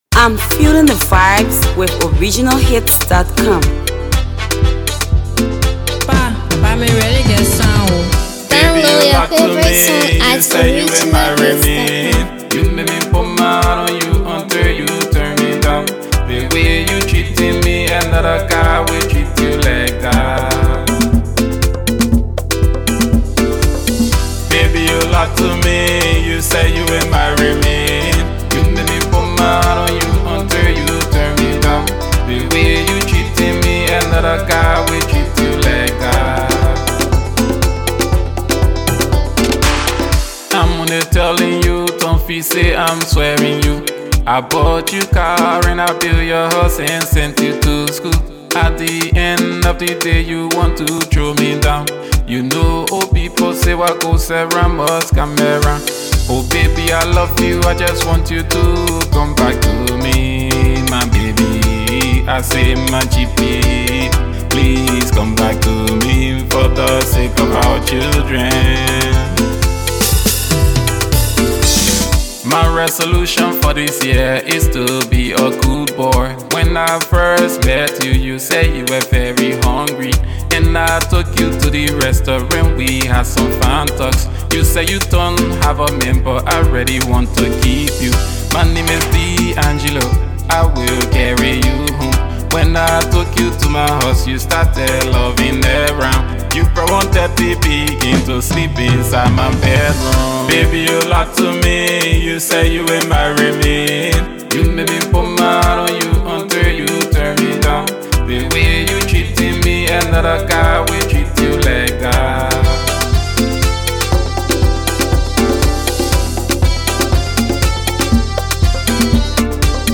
AfroAfro PopLATEST PLAYLISTMusic
It’s a nice masterpiece banger.